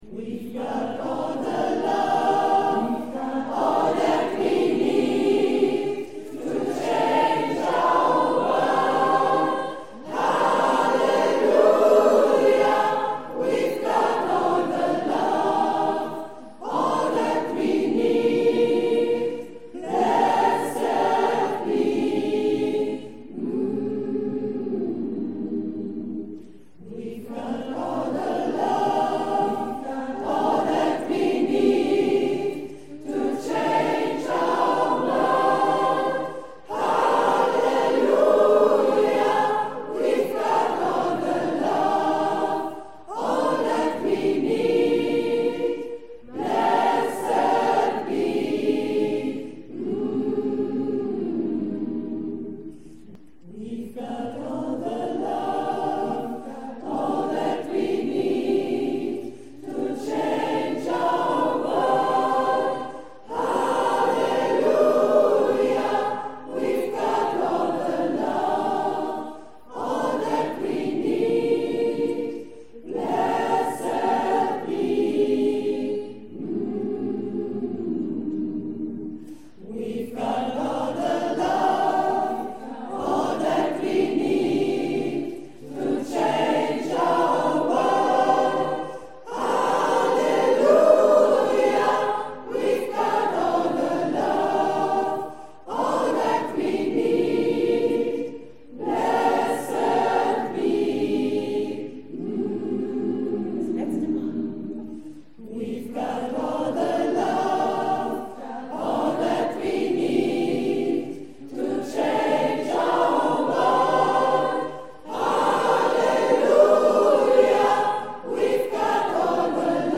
Singwochenende 11. - 13. März 2016